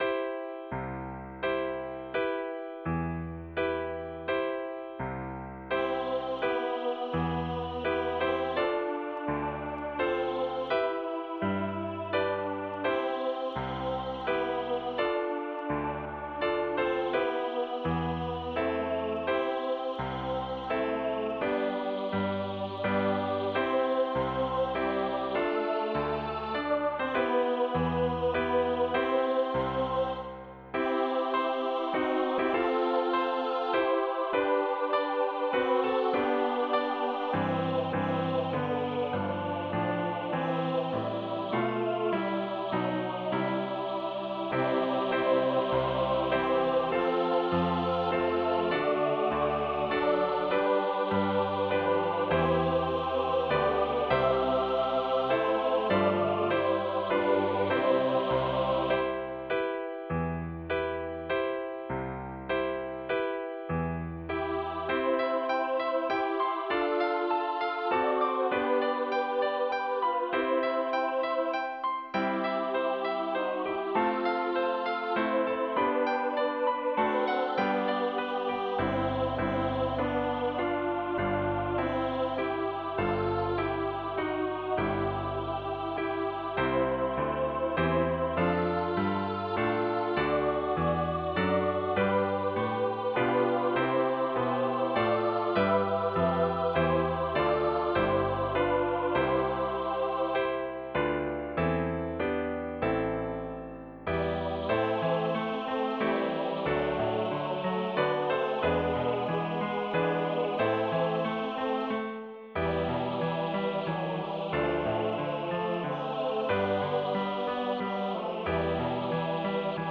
An SATB choir and piano arrangement
Voicing/Instrumentation: SATB